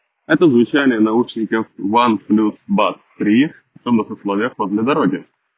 Микрофон OnePlus Buds 3 на 9 из 10 — отличный, как в шумных, так и в тихих условиях.
В шумных условиях:
oneplus-buds-3-shumno.m4a